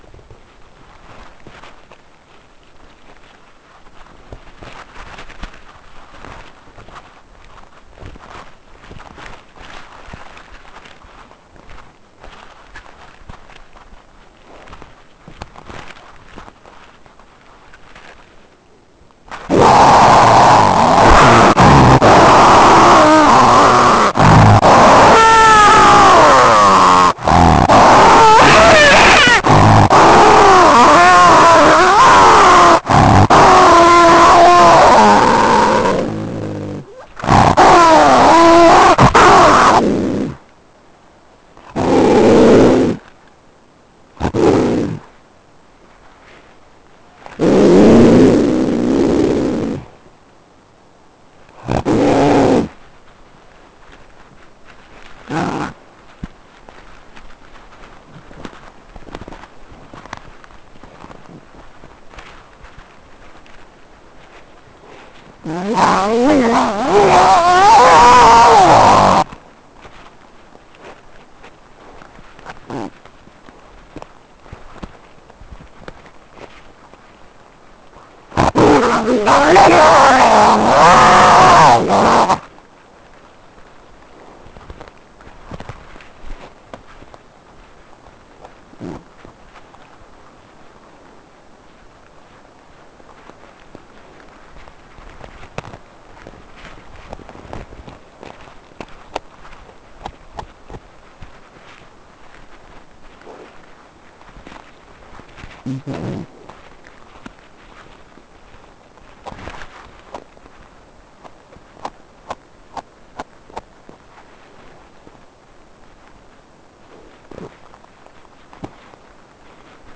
Appendix A8: (Supplementary Materials 8): Audio clip of kleptoparasitism (klepto_feed.wav) | Digital Collections